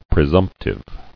[pre·sump·tive]